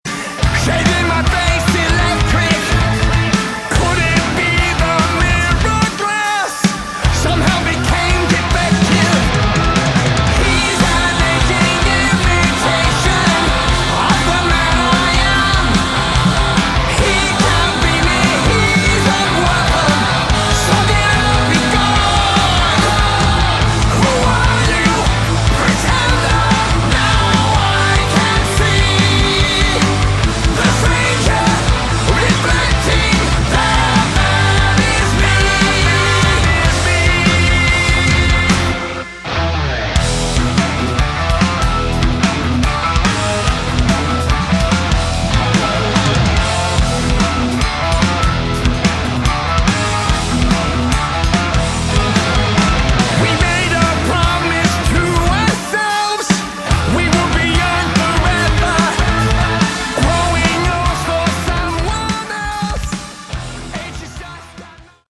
Category: Hard Rock
vocals
guitars
bass
keyboards
drums